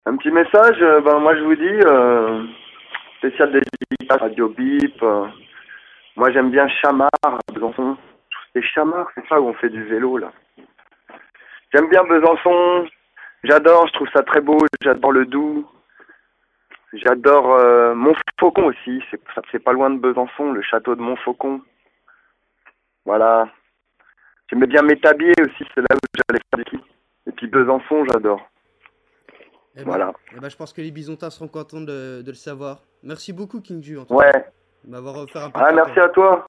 Télécharger_King_Ju_Extrait_Interview